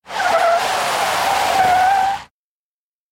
На этой странице собраны разнообразные звуки Змея Горыныча: от грозного рыка до зловещего шипения.
Шипящий гул торможения Змея Горыныча при посадке